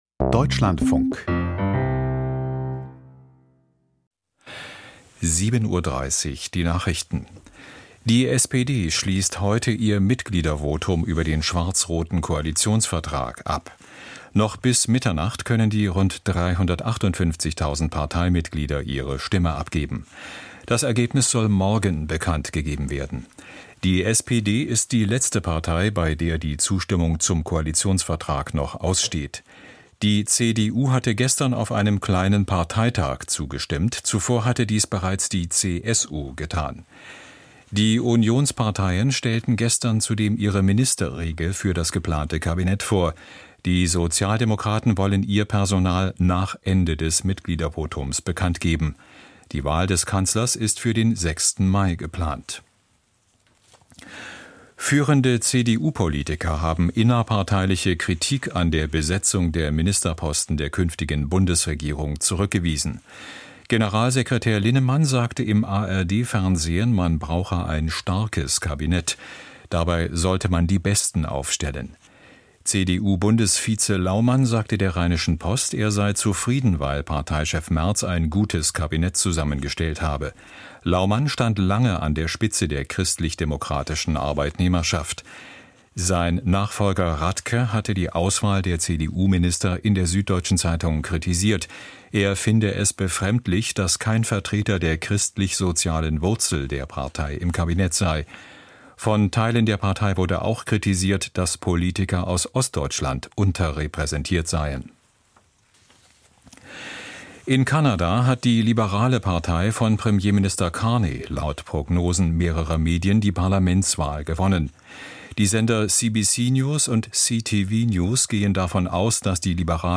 Die Deutschlandfunk-Nachrichten vom 29.04.2025, 07:30 Uhr